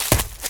STEPS Leaves, Run 21, Heavy Stomp.wav